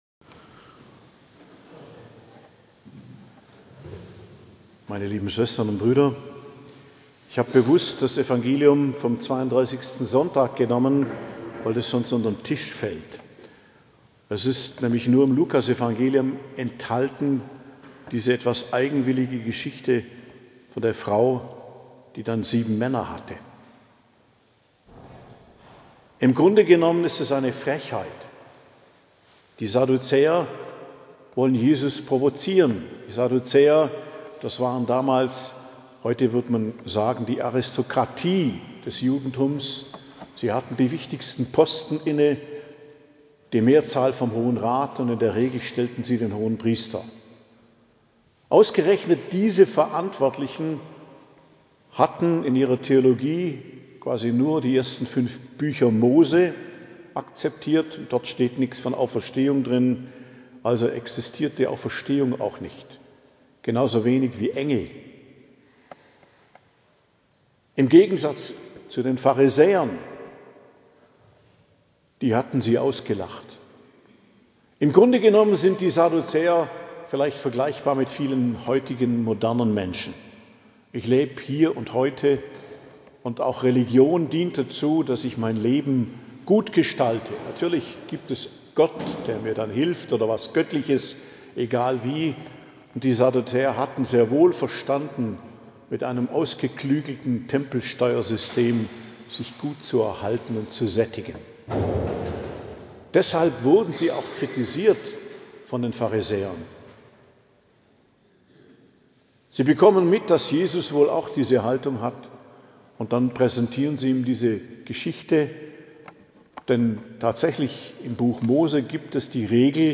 Predigt zum Weihetag der Lateranbasilika, am 32. Sonntag i.J., 9.11.2025